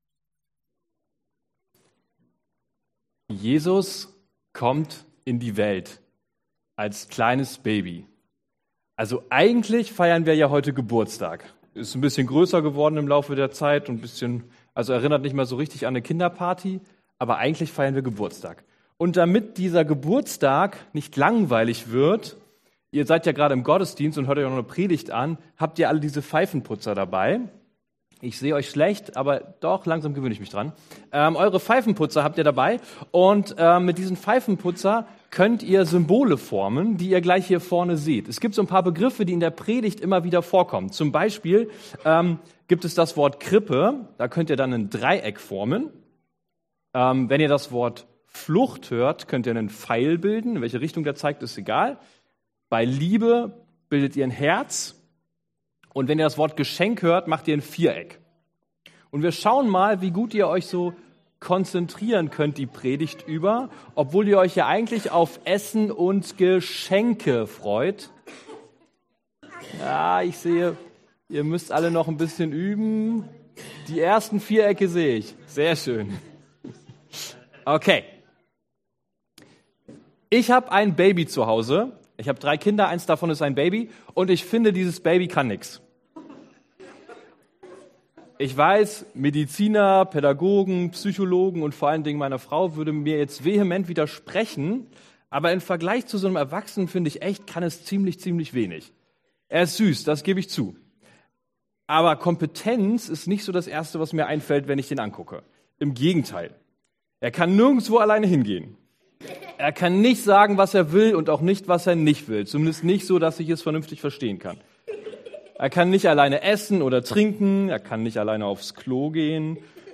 Matthäus 2,1-15 Dienstart: Predigt « Warum musste Jesus geboren werden?